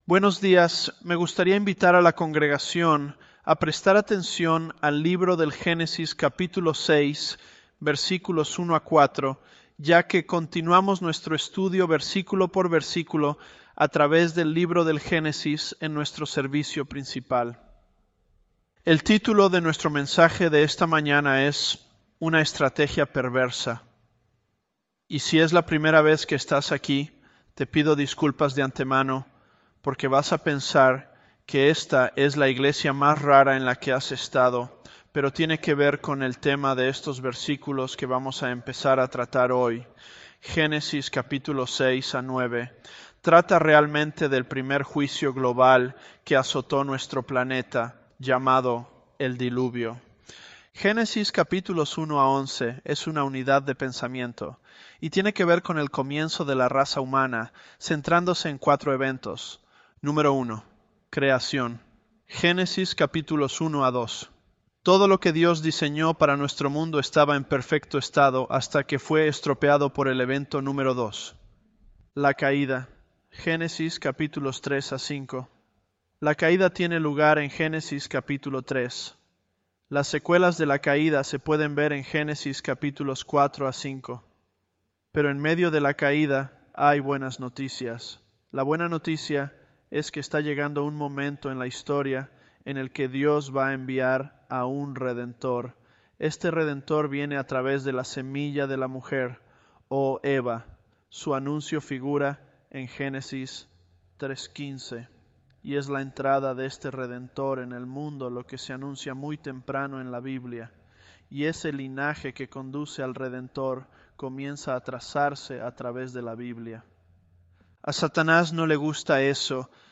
Sermons
ElevenLabs_Genesis-Spanish025.mp3